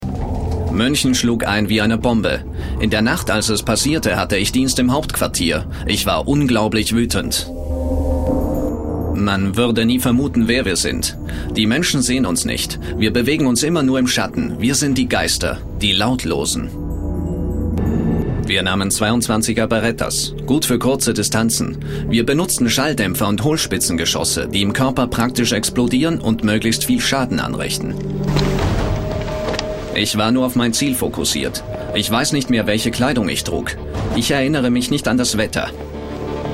Die Stimme ist kräftig, klar und ausdrucksstark. Das Stimmspektrum reicht von sonor, getragen bis jugendlich und energiegeladen.
Sprechprobe: Industrie (Muttersprache):